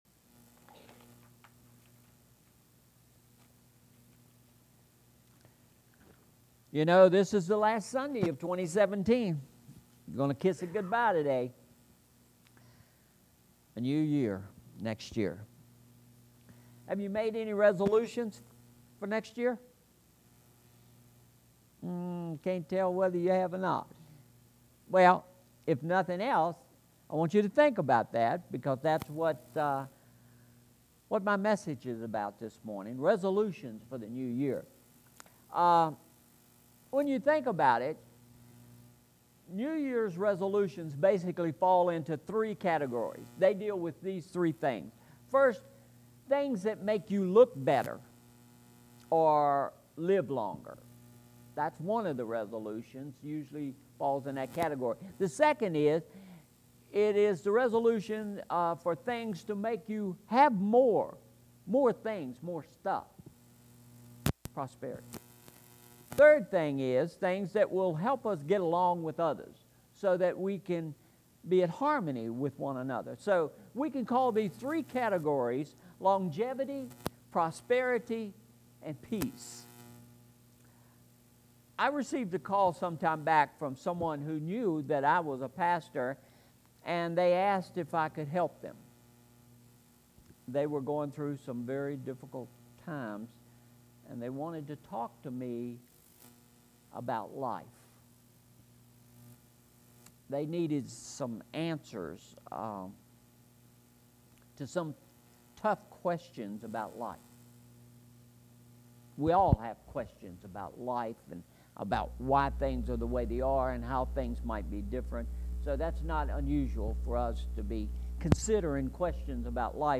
Message Aim: Eve of the New Year